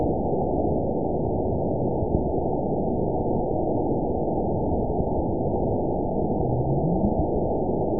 event 920290 date 03/13/24 time 00:10:23 GMT (1 year, 8 months ago) score 9.42 location TSS-AB03 detected by nrw target species NRW annotations +NRW Spectrogram: Frequency (kHz) vs. Time (s) audio not available .wav